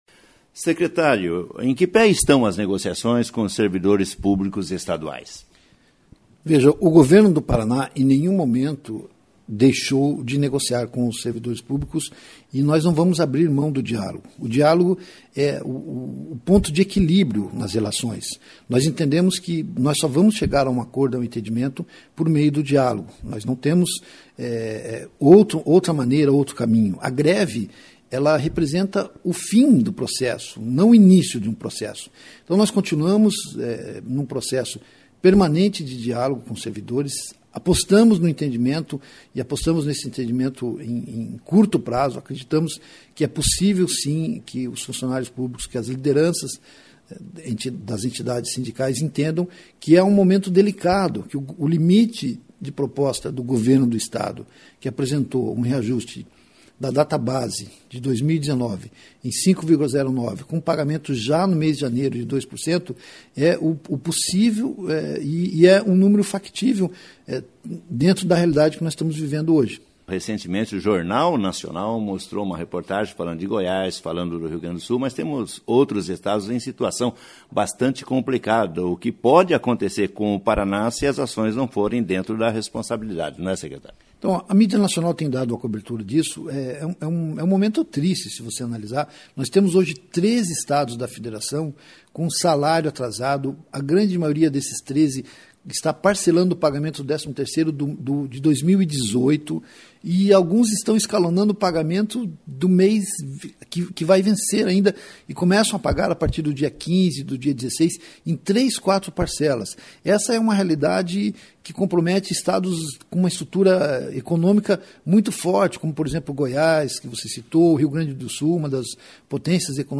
ENTREVISTA COM O SECRETÁRIO DE COMUNICAÇÃO SOCIAL E CULTURA, HUDSON JOSÉ
ENTREVISTA COM O SECRETÁRIO DE COMUNICAÇÃO SOCIAL E CULTURA, HUDSON JOSÉ 10/07/2019 ENTREVISTA COM O SECRETÁRIO DE COMUNICAÇÃO SOCIAL E CULTURA, HUDSON JOSÉ, SOBRE AS NEGOCIAÇÕES COM OS SERVIDORES ESTADUAIS.